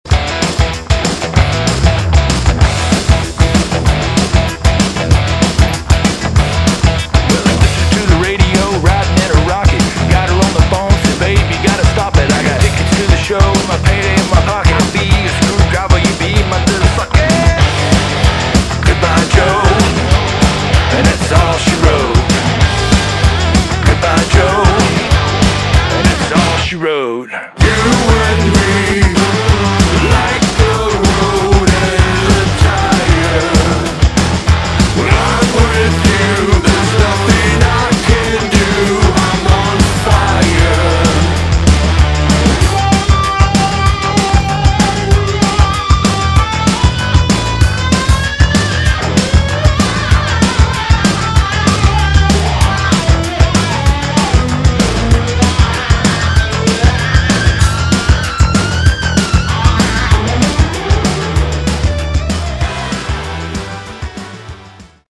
Category: Hard Rock / Boogie Rock
lead vocals, guitar
lead guitars